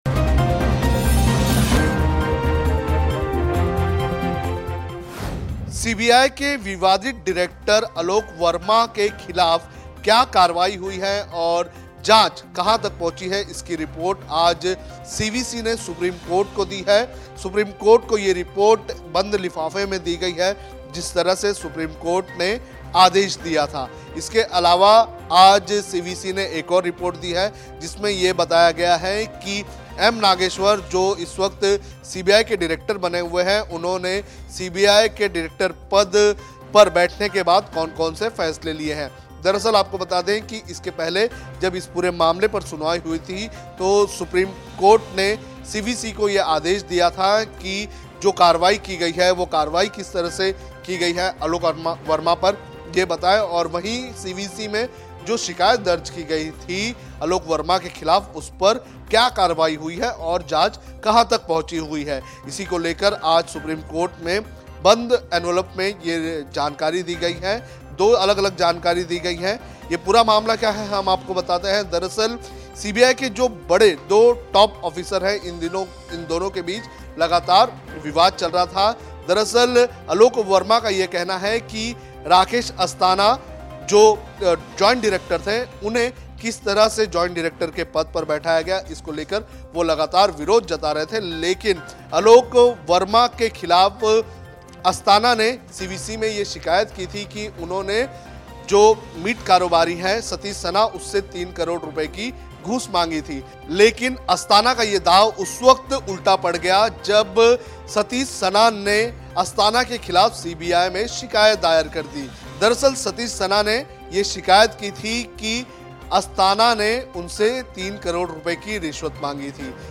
न्यूज़ रिपोर्ट - News Report Hindi / सीवीसी ने सीलबंद लिफाफों में रिपोर्ट सौंपी, शुक्रवार को कोर्ट सुनाएगी फैसला कौन है गुनहगार?